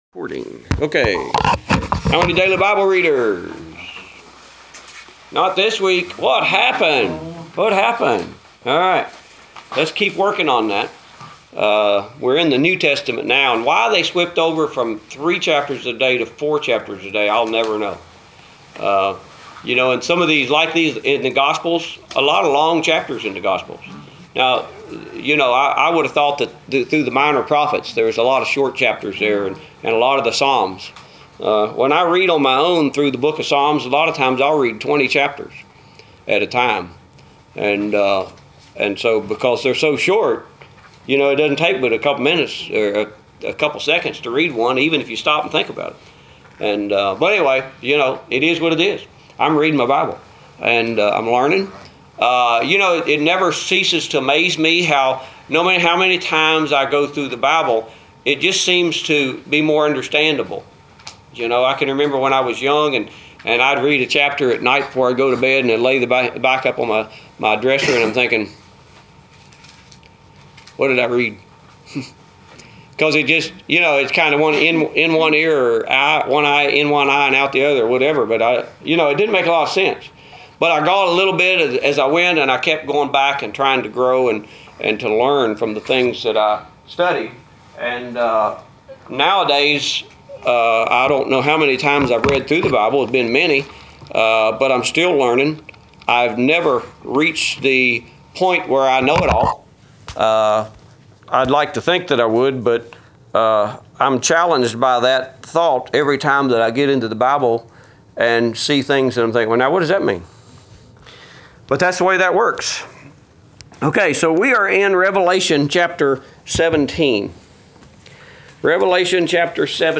Adult Bible Class: 10/29/2017